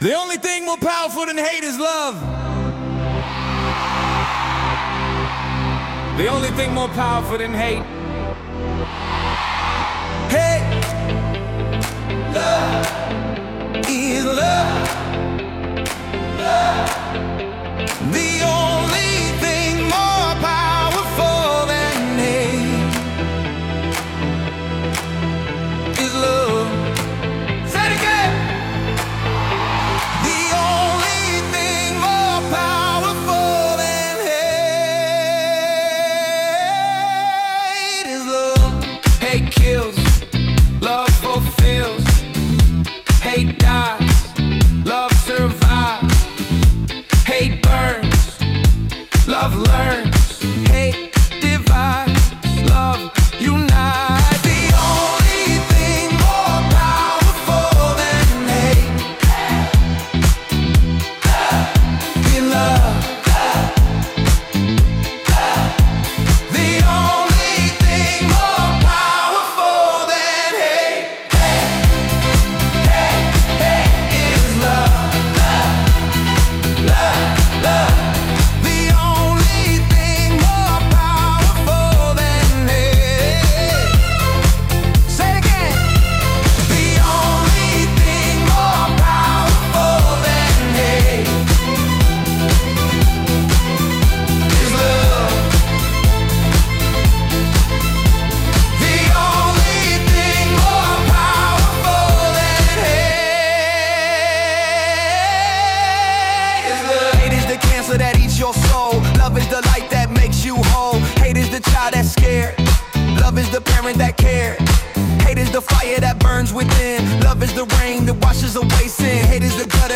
Music/Vocals: AI Generated